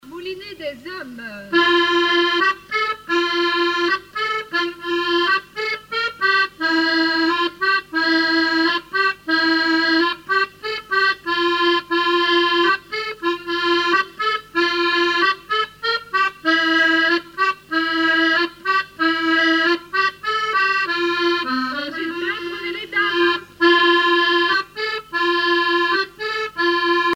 Grand'Landes
danse : quadrille : moulinet
Musique du quadrille local
Pièce musicale inédite